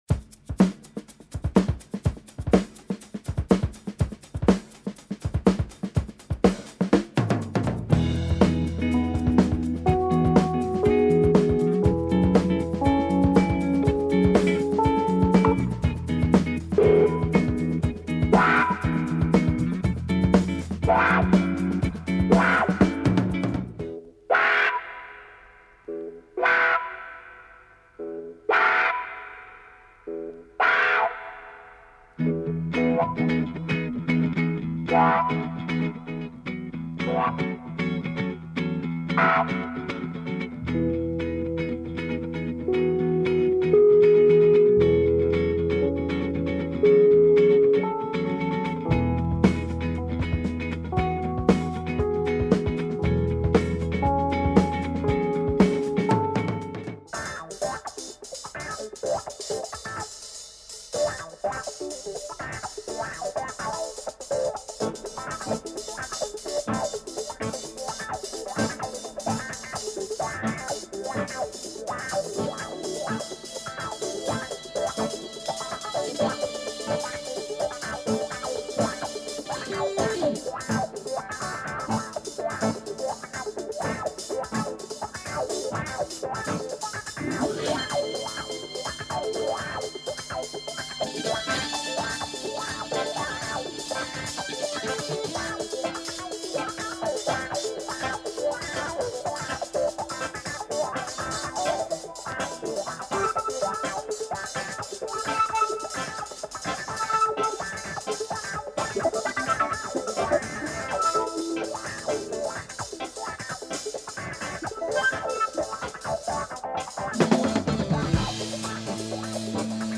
Killer funky breaks